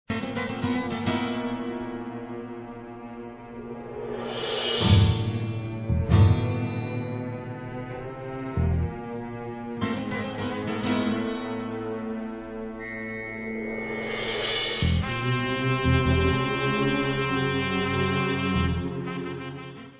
Spaghetti western magic from the meastro